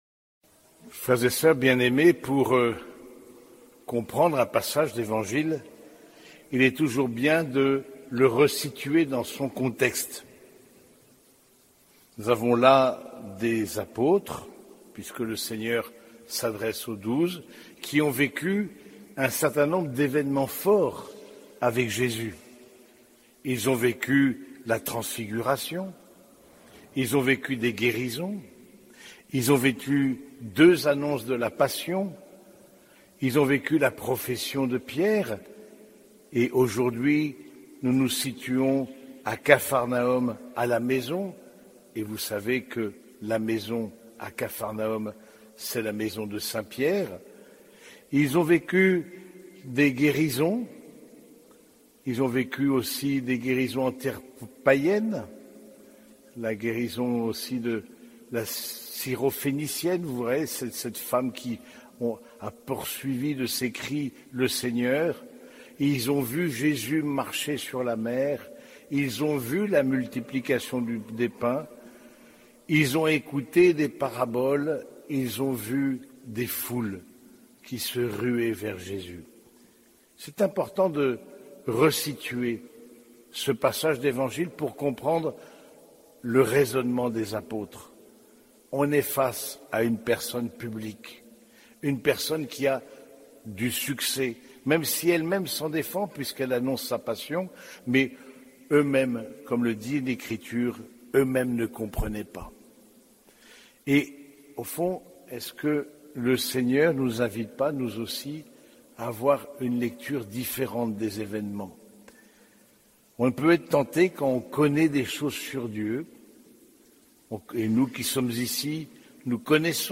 Homélie du 25e dimanche du Temps Ordinaire